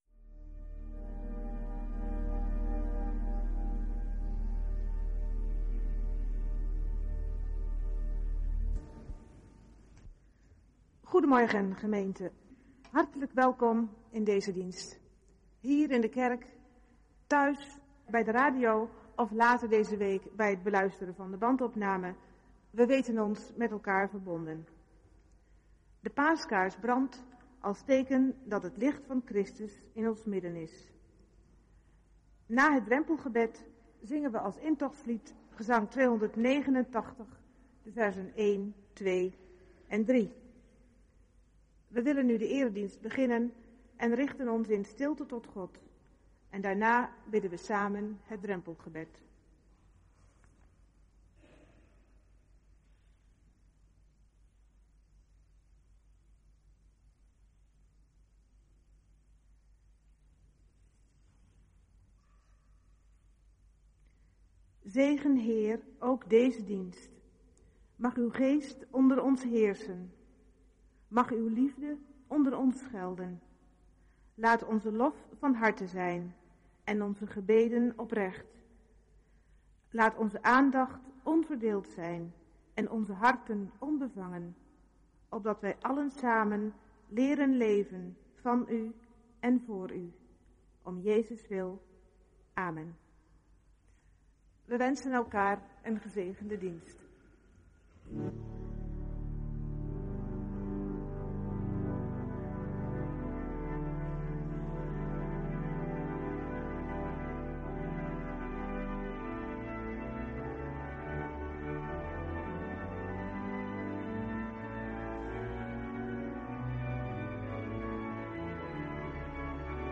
Dienst beluisteren (de geluidskwaliteit is na de 45 minuut minder) Orde van de dienst